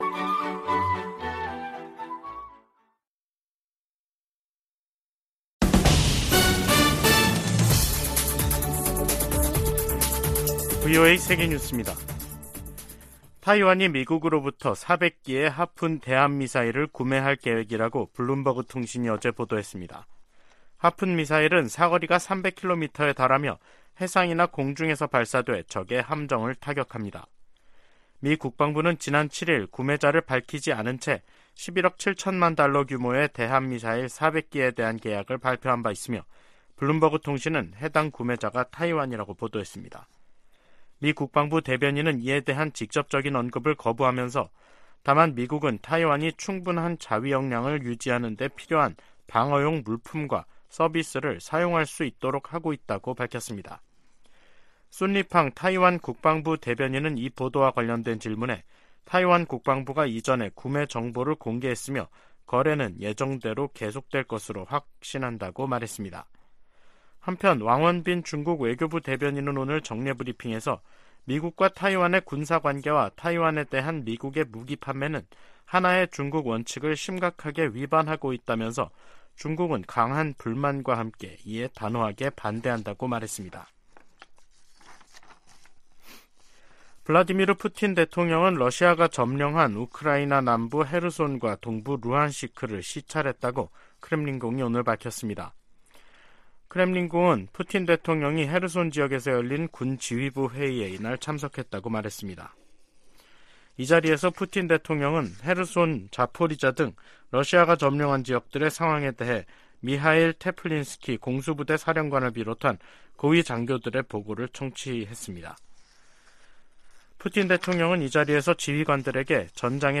VOA 한국어 간판 뉴스 프로그램 '뉴스 투데이', 2023년 4월 18일 2부 방송입니다. 백악관은 정보당국의 도·감청 문건과 관련해 신속한 조치를 취하고 있으며, 파트너 국가들과의 신뢰는 훼손되지 않았다고 평가했습니다. 미 국방부는 도·감청 문제와 관련, 특히 한국과는 매우 좋은 관계를 유지하고 있다고 강조했습니다. 유엔 안전보장이사회가 북한의 신형 대륙간탄도미사일(ICBM) 관련 공개회의를 열고 반복 도발을 강하게 규탄했습니다.